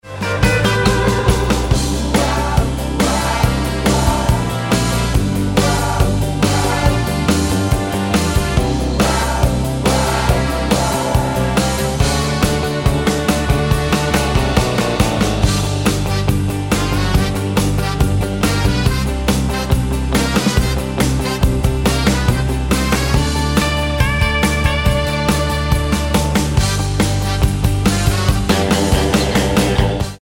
Tonart:G mit Chor
Die besten Playbacks Instrumentals und Karaoke Versionen .